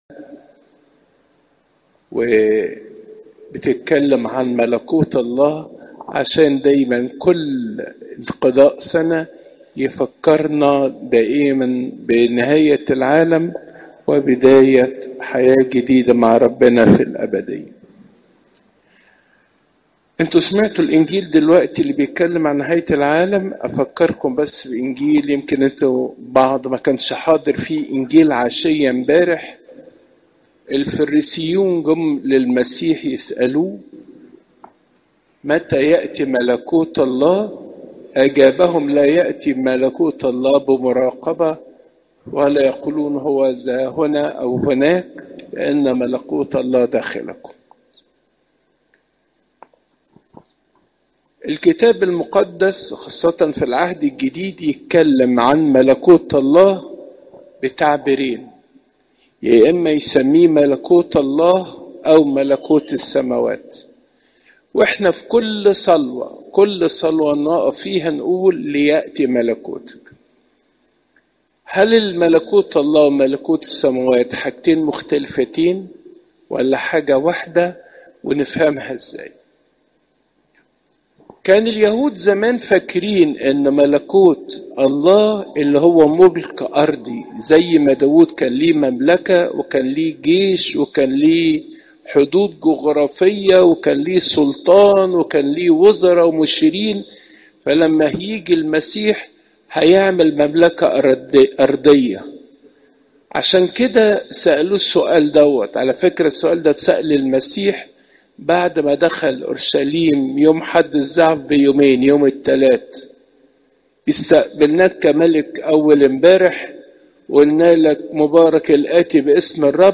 عظات قداسات الكنيسة (مر 13 : 3 - 37)